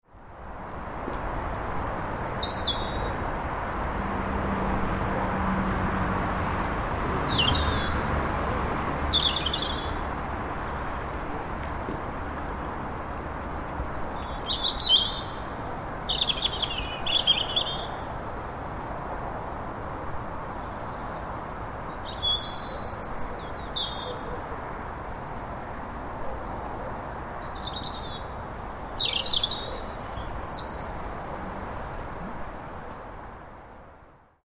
Birdsong, suburban Shepshed
Shepshed, Leicestershire - birdsong and the hum of the nearby M1 motorway.